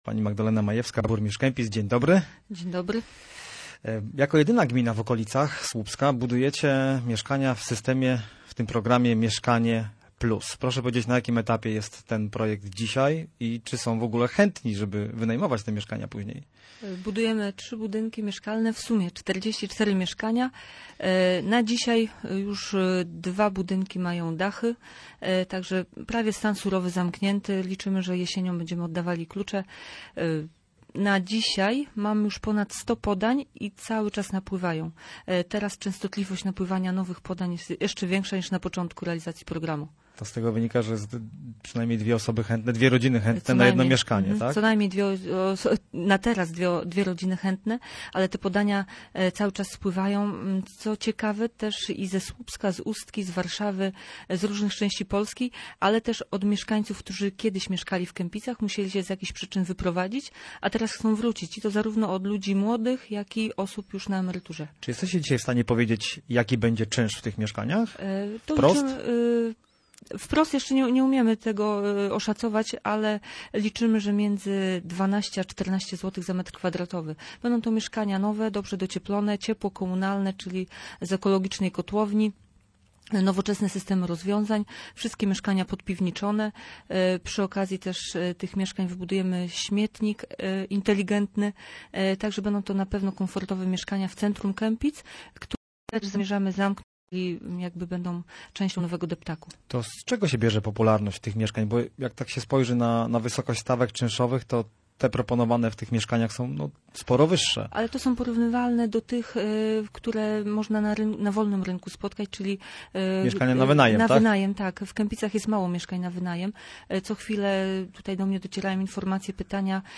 – Ciągle napływają nowe podania o przydział lokalu z programu Mieszkanie Plus – powiedziała w Radiu Gdańsk Magdalena Majewska, burmistrz Kępic.
Gość miejskiego programu Radia Gdańsk w Słupsku zauważyła, że wśród chętnych jest wiele osób, które kiedyś wyprowadziły się z Kępic i teraz chcą tam wrócić. Prace budowlane trzech powstających budynków, są już na zaawansowanym etapie.